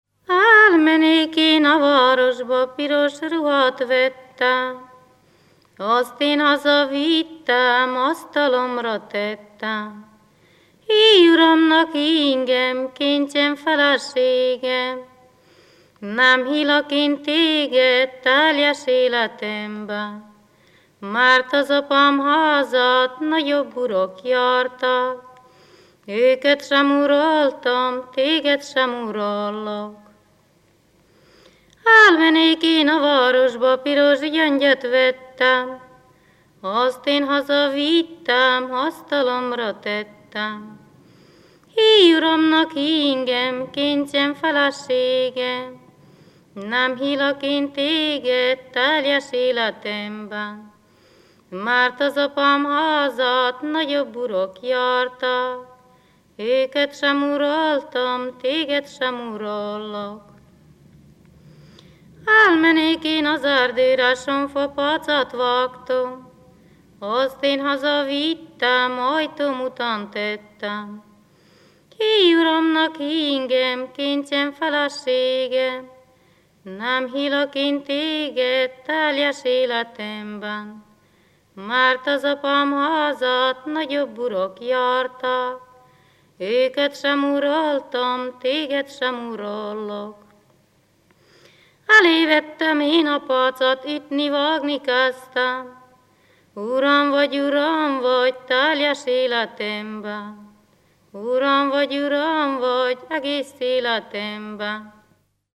ének
ballada
Moldva (Moldva és Bukovina)